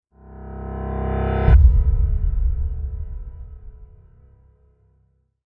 Intense Piano Riser With Deep Impact Sound Effect
Description: Intense piano riser with deep impact sound effect. Experience an intense piano riser that builds suspense and ends with a powerful deep impact.
Genres: Sound Effects
Intense-piano-riser-with-deep-impact-sound-effect.mp3